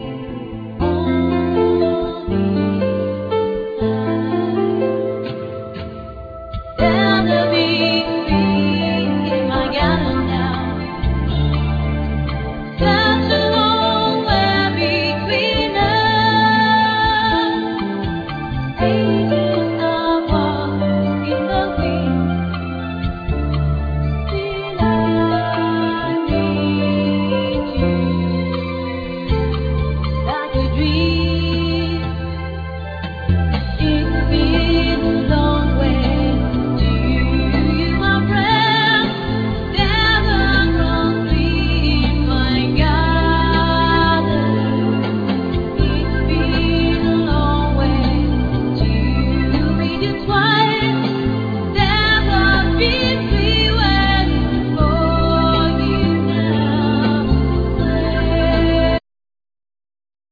Piano,Keyboards,Programming
Guitar
Drums,Percussions
Flute